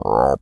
frog_deep_croak_01.wav